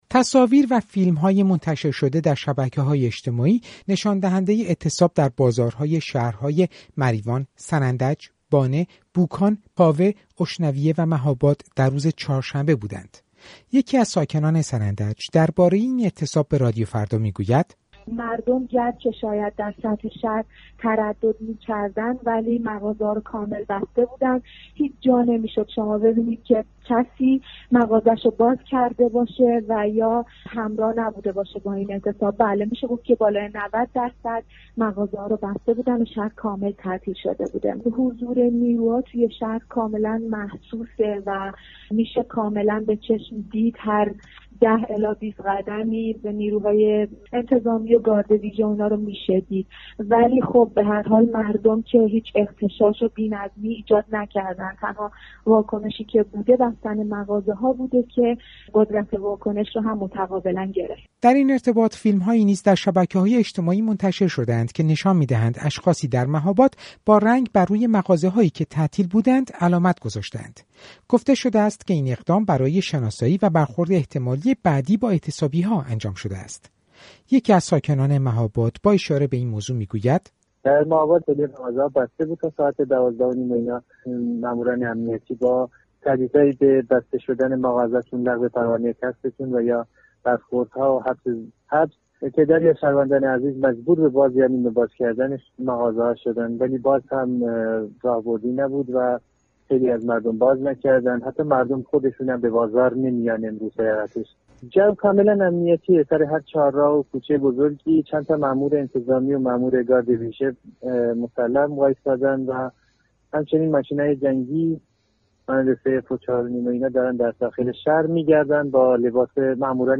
گزارش رادیویی از اعتصاب در مناطق کردنشین ایران